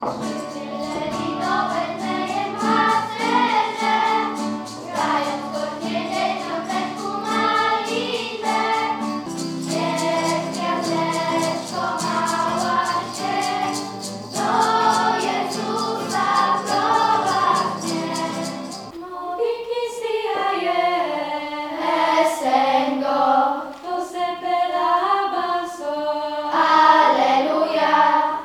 Dzieci zaśpiewały również kolędy, w tym jedną w rdzennym języku kongo.